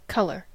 Ääntäminen
IPA : /ˈkʌl.ə(ɹ)/